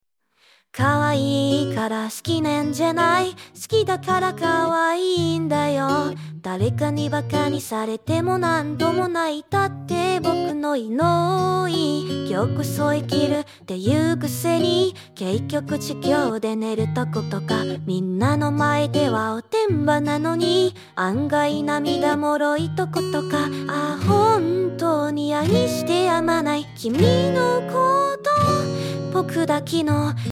唱歌表现